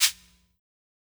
SHAKER_SAD.wav